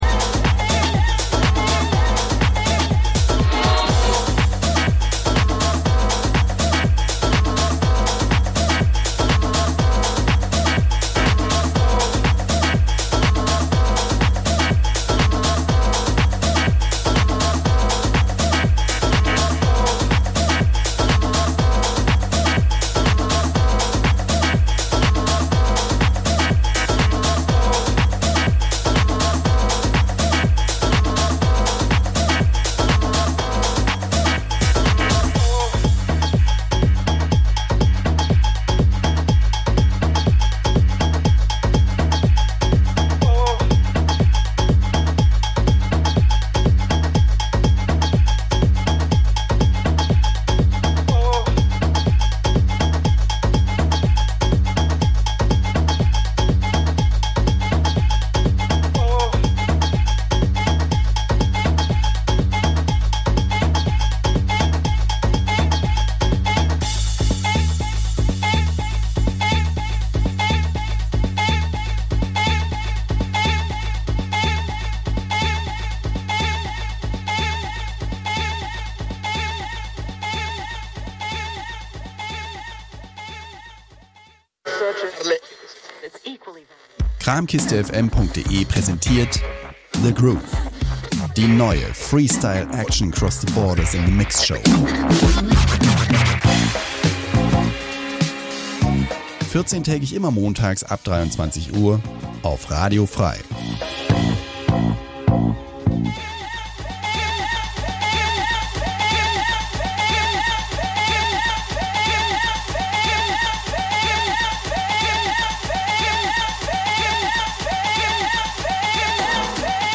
Experimental, Dub, Electronica, etc The Groove l�uft jeden zweiten Montag 23-01 Uhr und wird wechselnd am 1.
House, Drum�n�Bass, Breaks, Hip Hop, ...